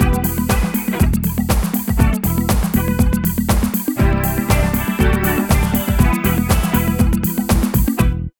78 LOOP   -L.wav